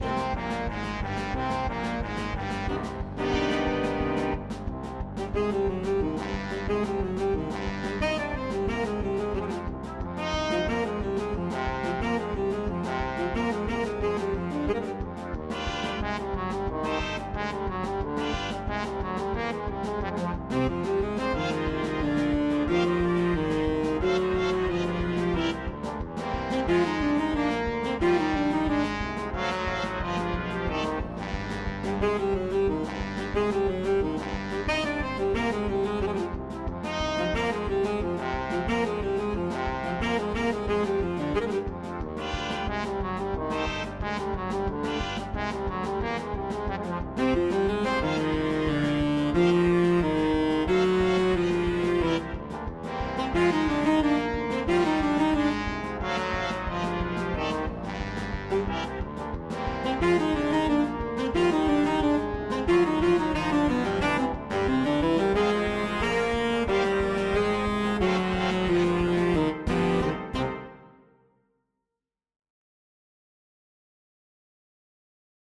Партитура для ансамбля
*.mid - МИДИ-файл для прослушивания нот.